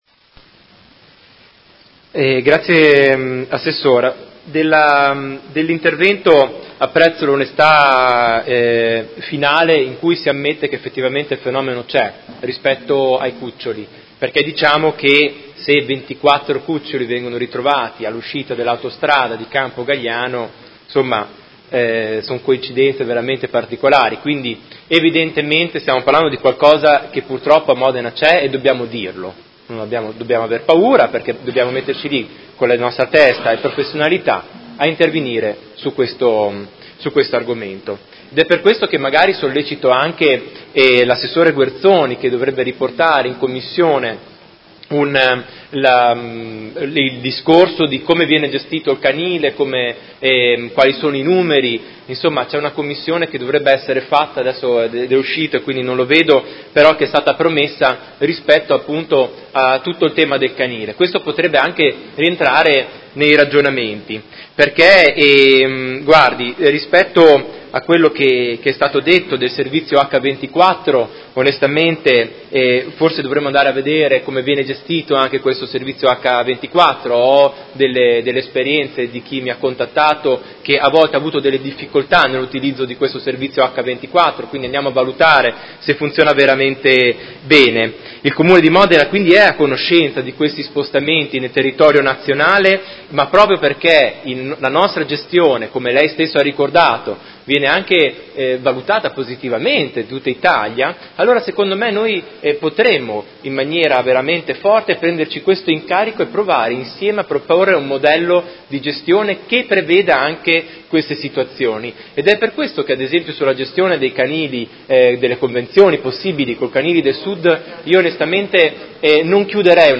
Seduta del 15/11/2018 Replica a risposta Assessora Filippi. Interrogazione del Consigliere Chincarini (Art1-MDP/Per Me Modena) avente per oggetto: Situazione traffico/staffette cani